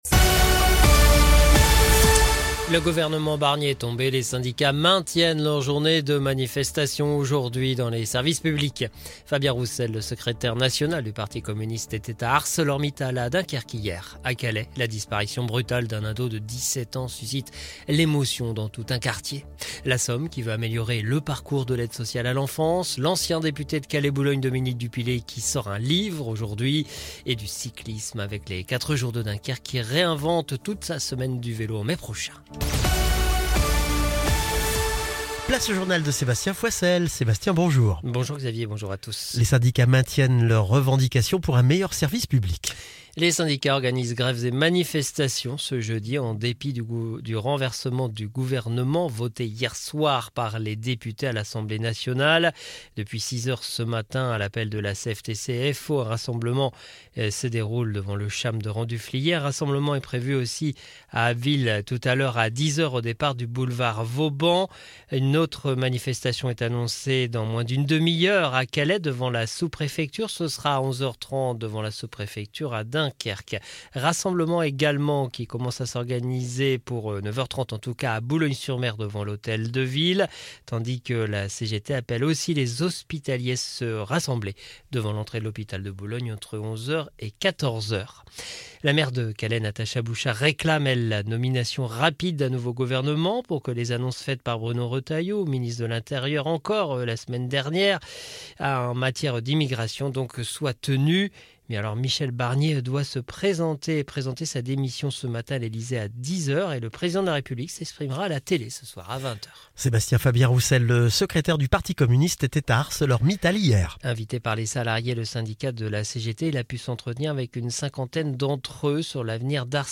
Le journal du jeudi 5 décembre 2024